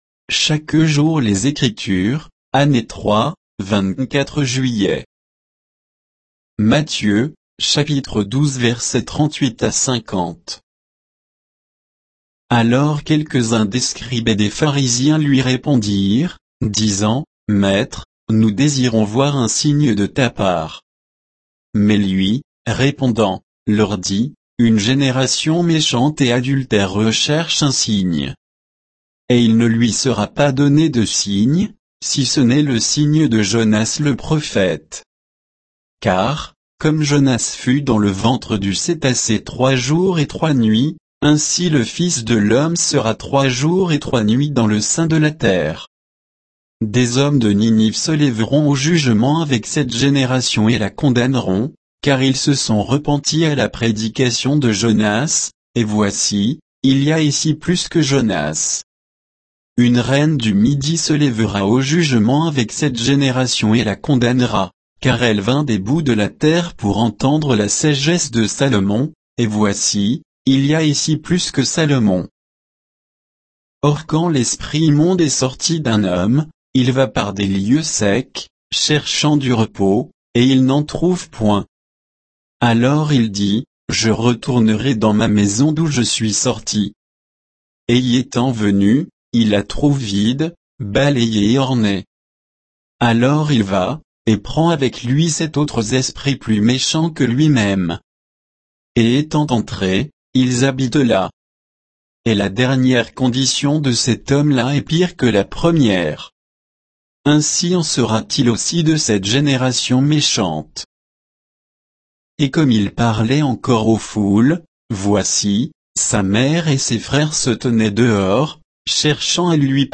Méditation quoditienne de Chaque jour les Écritures sur Matthieu 12, 38 à 50